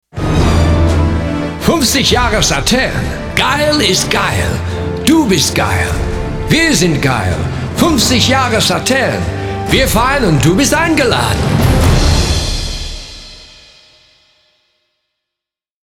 english speaker, englischer sprecher, mittlere Stimme
Sprechprobe: Werbung (Muttersprache):
Warm voice, very flexible for animation and character work.